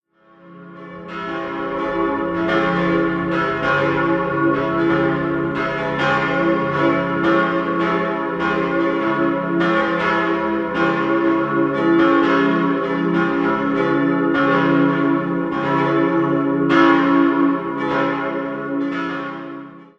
Er ließ sich von der Anlage der Kirche in Callenberg (Kreis Hohenstein-Ernstthal) anregen. 3-stimmiges Geläut: cis'-e'-gis'(+) Die Glocken 1 und 2 wurden 1866 von J. G. Große in Dresden gegossen, die kleine stammt von Franz Schilling Söhne aus dem Jahr 1935.